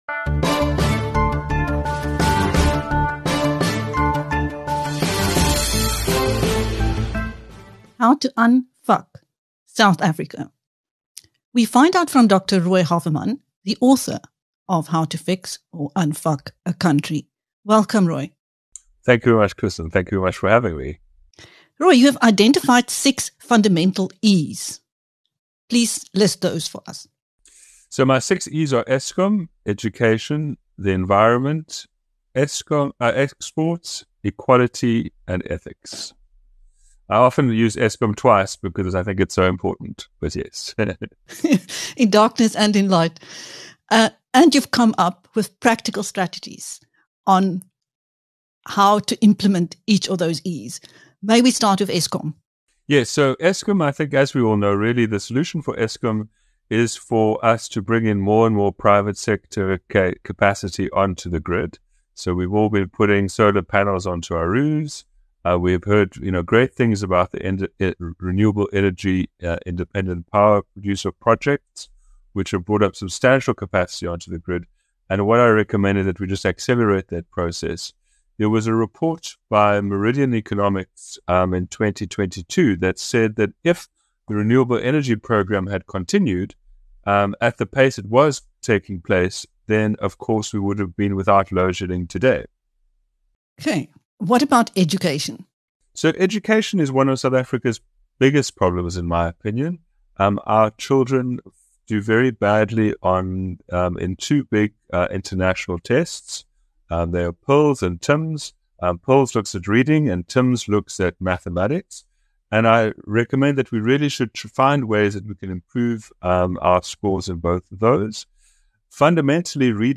Drawing from consultations with esteemed entities, he illustrates how nations rebounded from crises, advocating for swift governmental action. This insightful interview with BizNews charts a path for South Africa's transformation into a powerhouse.